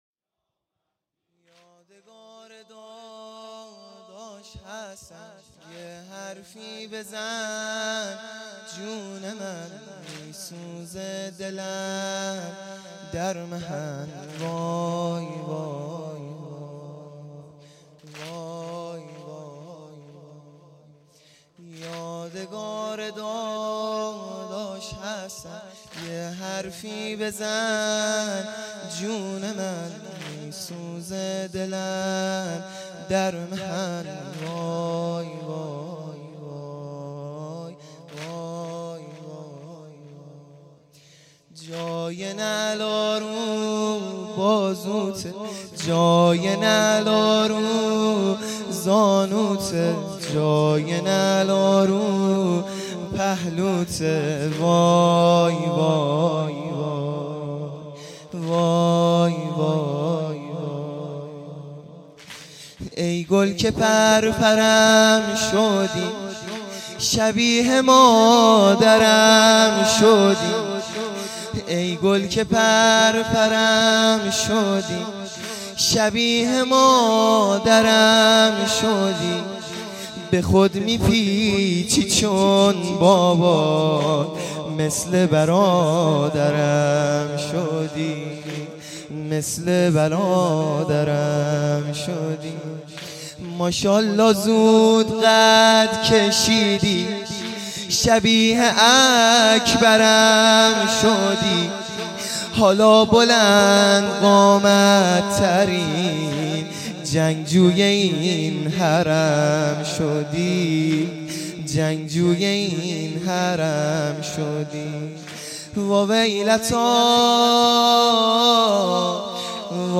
شب ششم محرم الحرام ۱۳۹۶